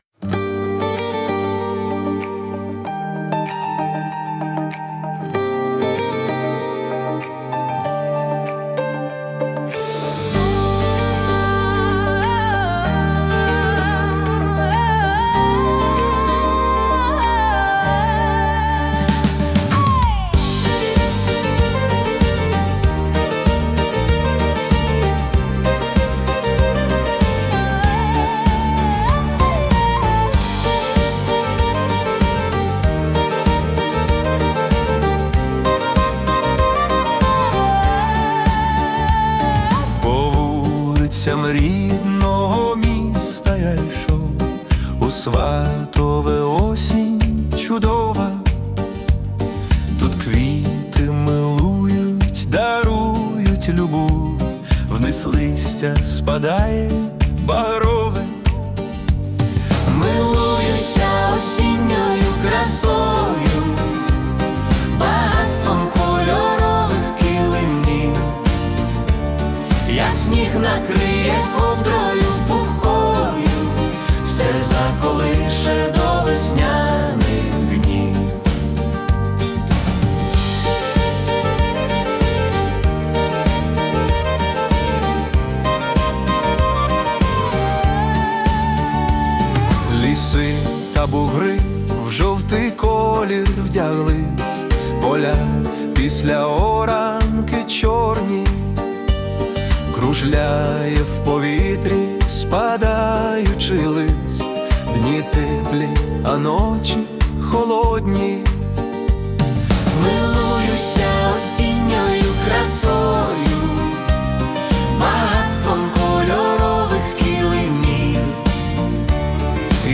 🎵 Жанр: Folk-Pop / Nostalgic
це тепла і мелодійна пісня (100 BPM)